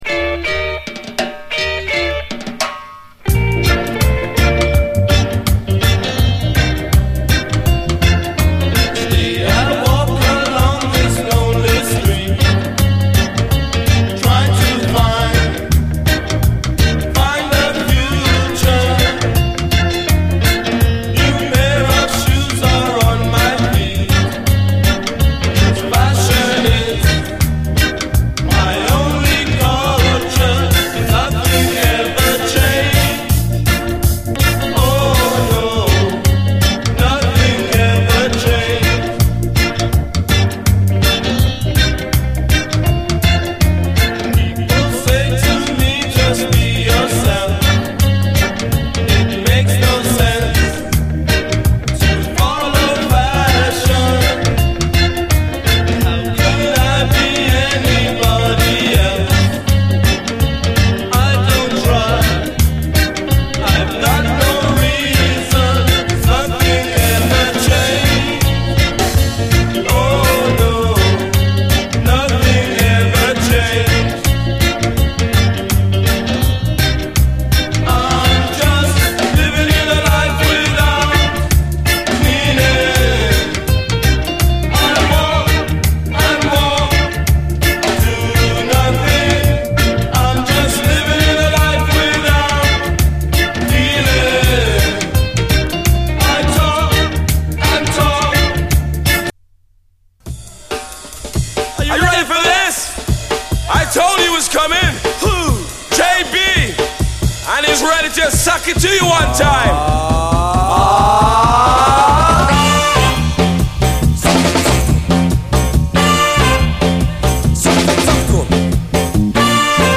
80's～ ROCK, REGGAE, ROCK